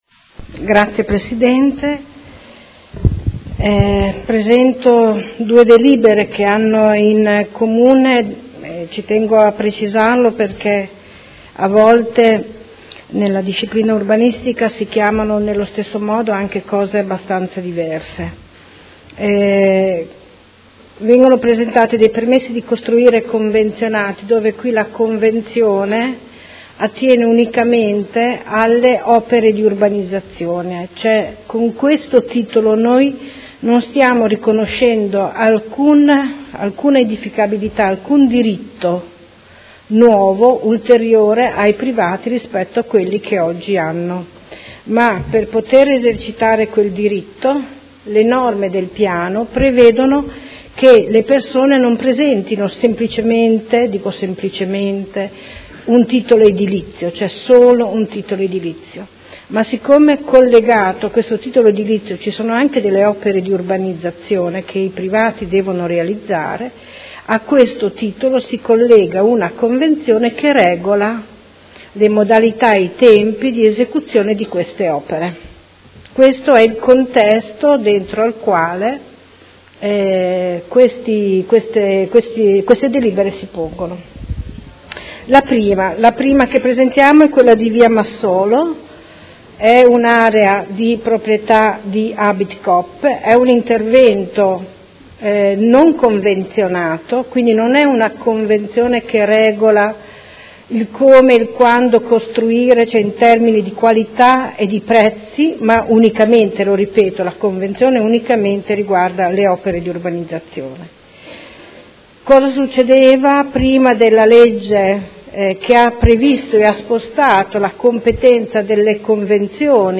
Seduta del 6 ottobre. Proposta di deliberazione: Permesso di costruire convenzionato per la realizzazione dell’intervento di trasformazione urbanistico-edilizia dell’area denominata “Abitcoop – Via Massolo”, zona elementare n. 1250 – area 06.